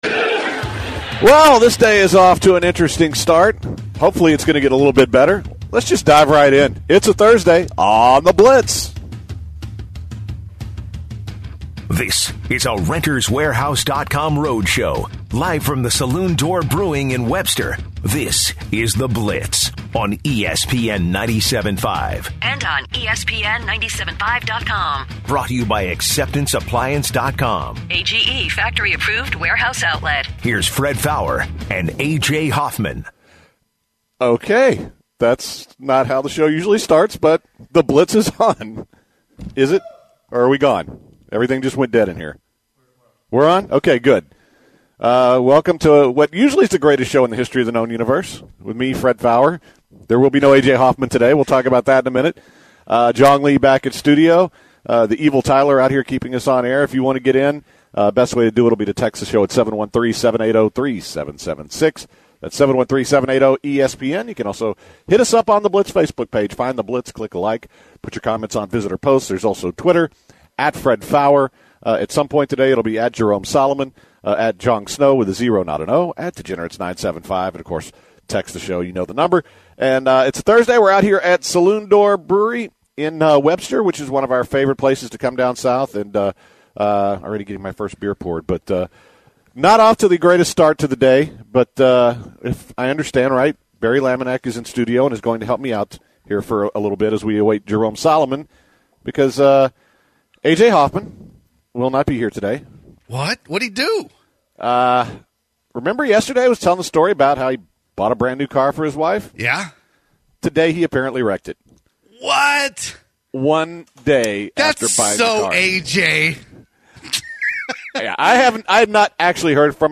The guys preview tonight’s Louisville/Houston showdown live from Saloon Door Brewing.